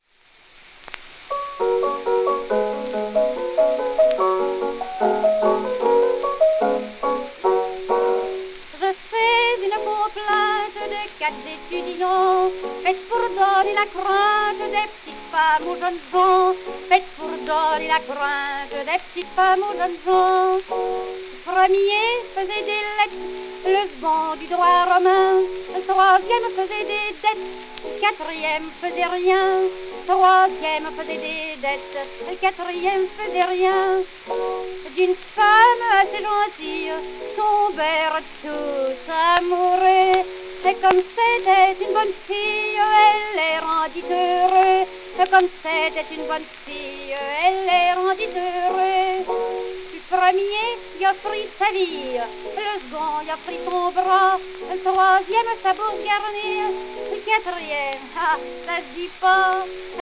盤質A-, キズ  ※画像クリックで試聴音源が流れます